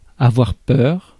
Ääntäminen
IPA: /a.vwaʁ pœʁ/